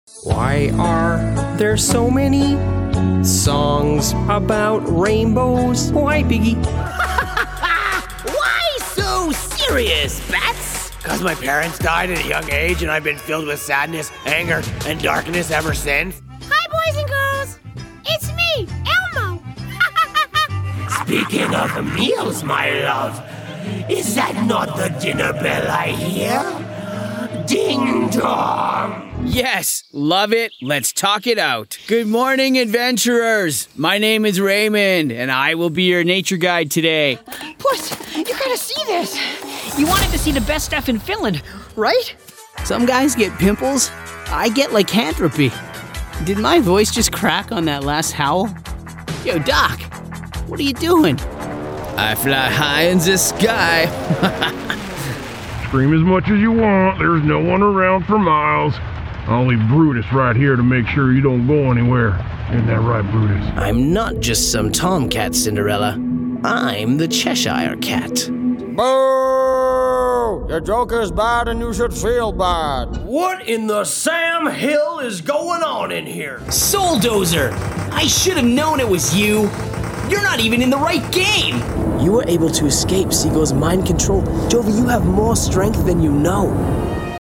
Englisch (Kanadisch)
Animation
Erzählung
-Professionelles Studio mit Tonkabine